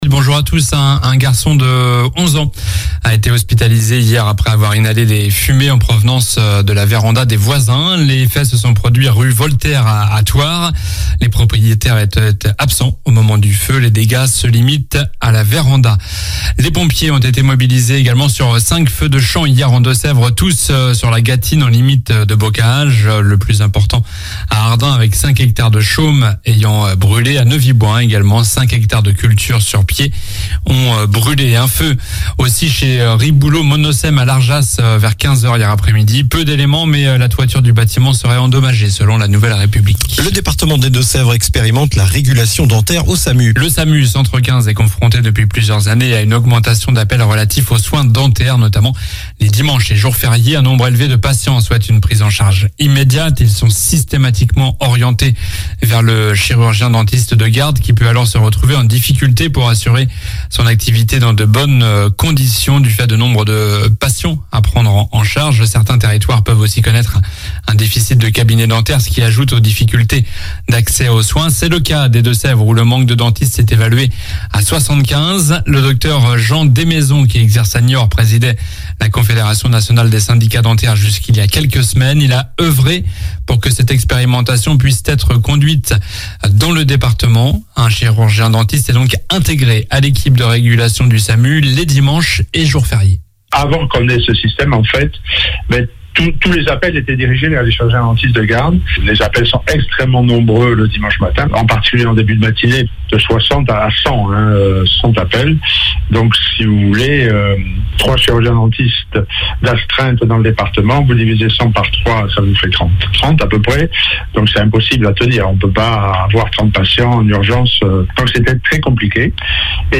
Journal du vendredi 22 juillet (matin)